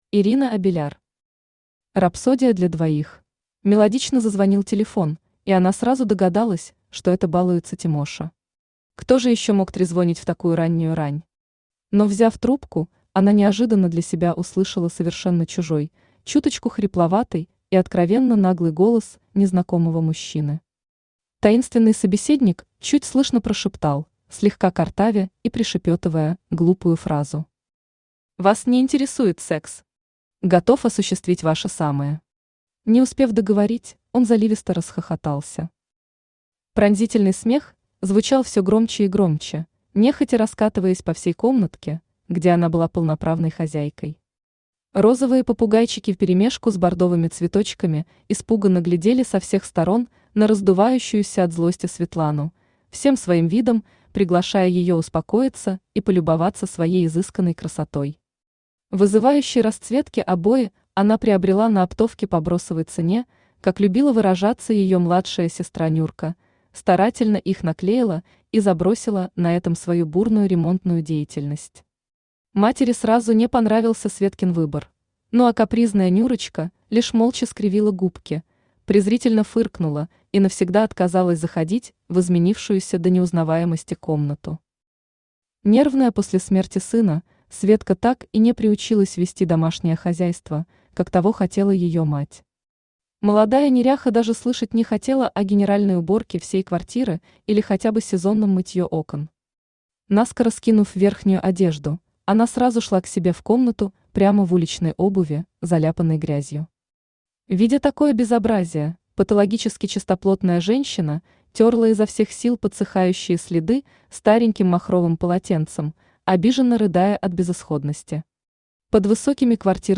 Аудиокнига Рапсодия для двоих | Библиотека аудиокниг
Aудиокнига Рапсодия для двоих Автор Ирина Абеляр Читает аудиокнигу Авточтец ЛитРес.